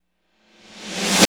55.07 SNR.wav